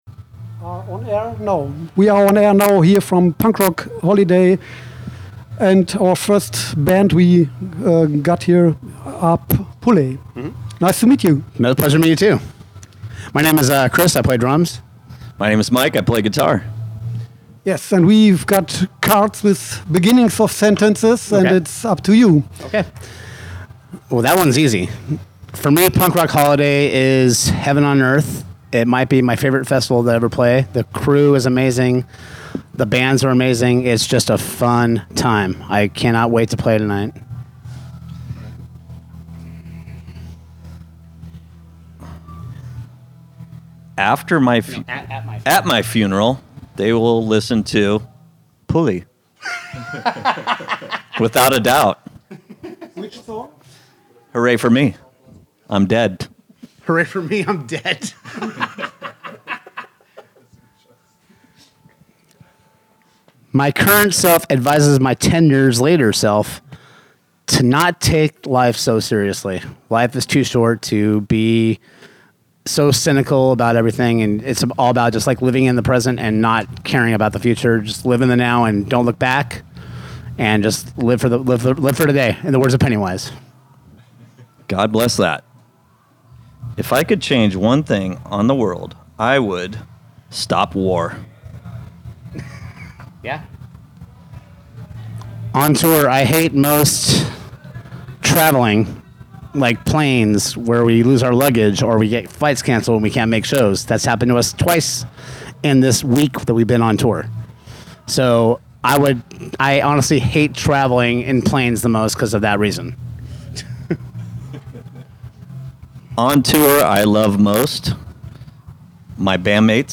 Letzte Episode Pulley Interview @ Punk Rock Holiday 1.9 20. August 2019 Nächste Episode download Beschreibung Teilen Abonnieren Pulley visited us in our festival radio studio before there gig at the warm up evening of Punk Rock Holiday.
pulley-interview-punk-rock-holiday-1-9-mmp.mp3